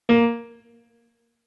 MIDI-Synthesizer/Project/Piano/38.ogg at 51c16a17ac42a0203ee77c8c68e83996ce3f6132